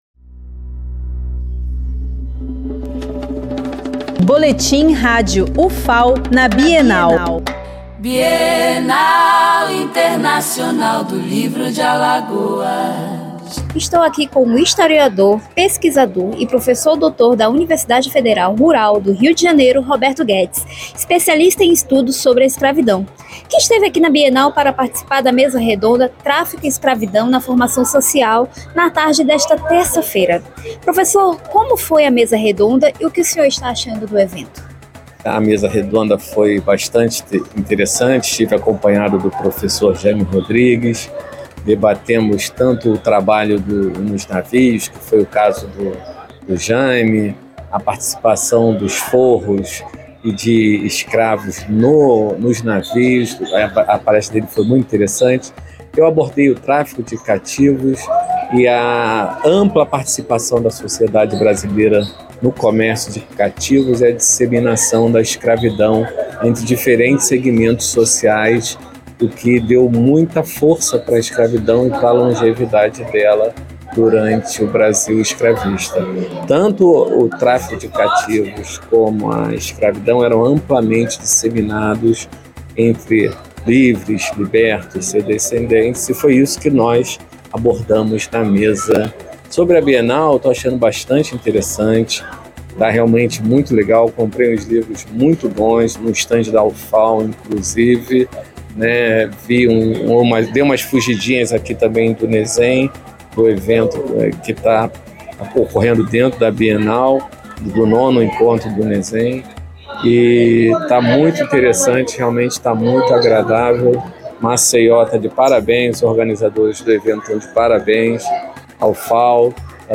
Flashes com informações da 11ª Bienal Internacional do Livro de Alagoas, realizada de 31 de outubro a 9 de novembro de 2025